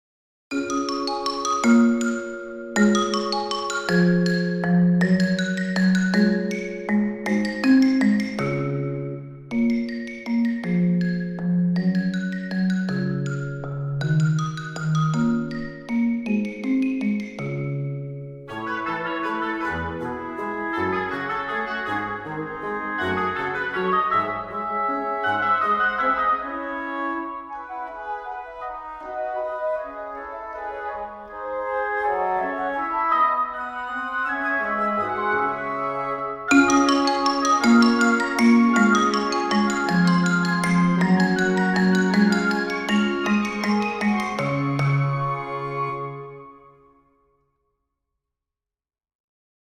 Van de stukken voor orkest is er met behulp van StaffPad een synthetische "weergave" worden gemaakt.
Op.54 No.6 Quarantino Symfonieorkest augustus 2025 Melodisch slagwerk en houtblazers